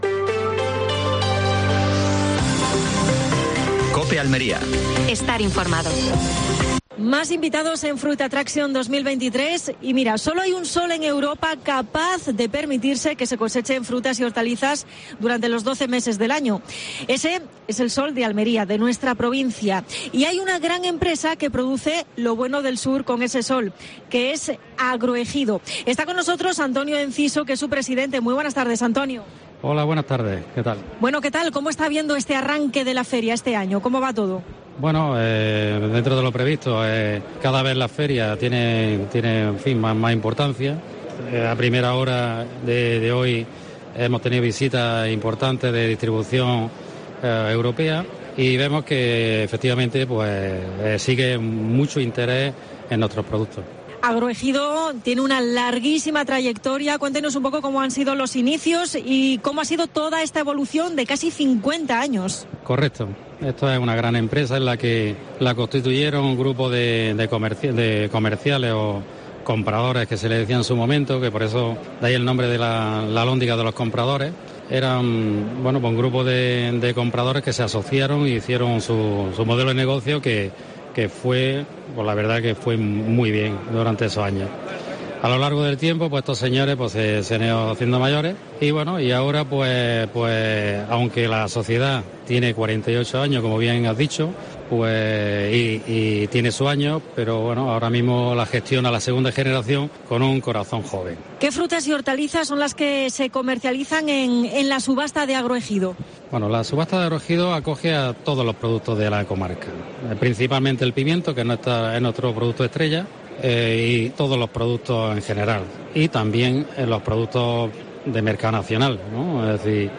AUDIO: Programa especial desde Fruit Attraction (Madrid).